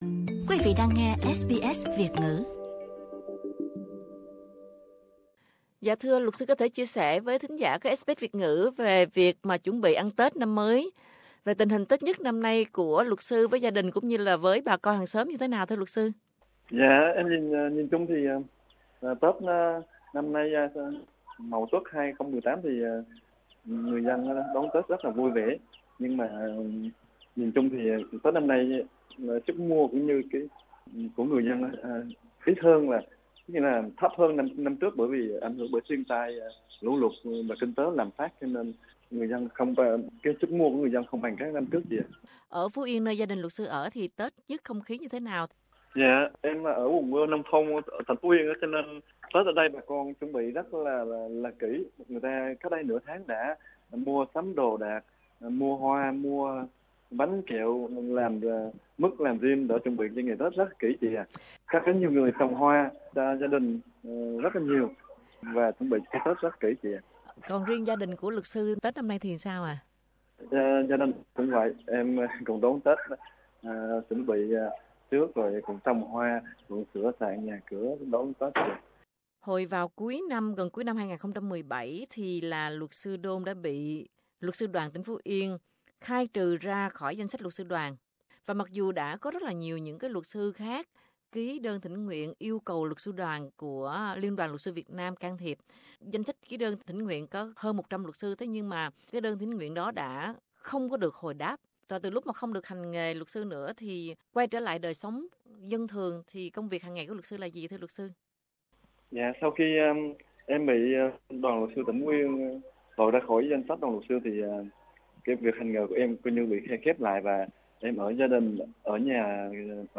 có thể nghe được âm thanh thân thuộc của một làng quê Việt Nam
có thể nghe được sự yên bình trong giọng nói của anh